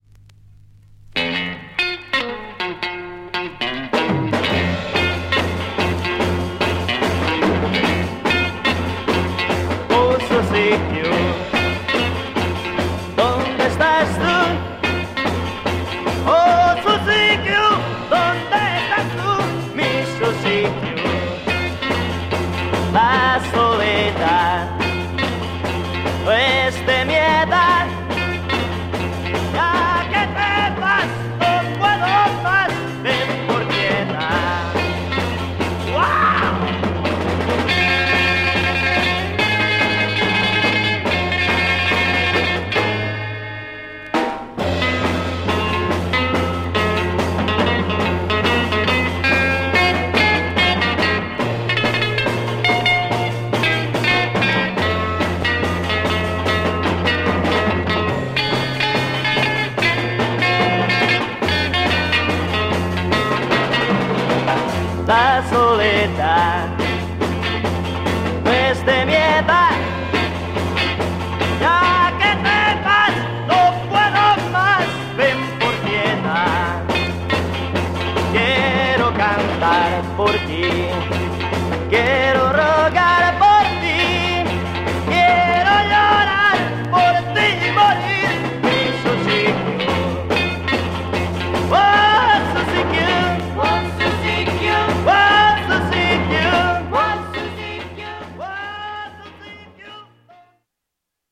Mega rare Spanish EP Garage Freakbeat